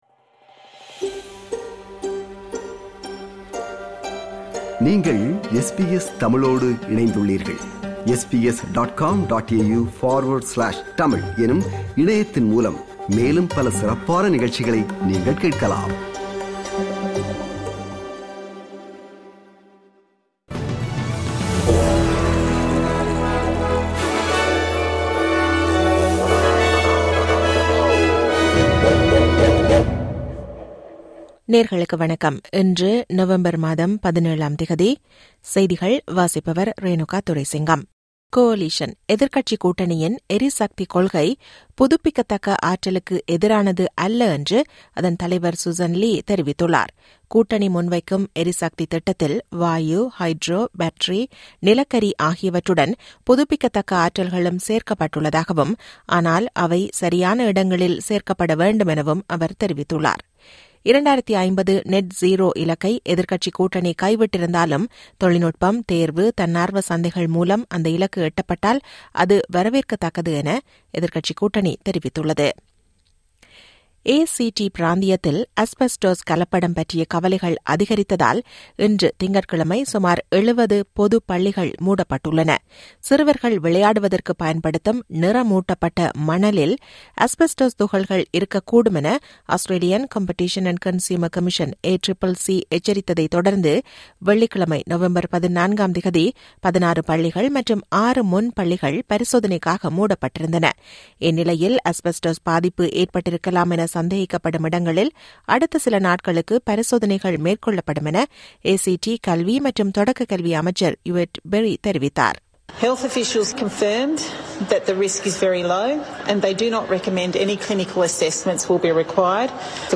SBS தமிழ் ஒலிபரப்பின் இன்றைய (திங்கட்கிழமை 17/11/2025) செய்திகள்.